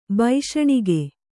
♪ baiśaṇige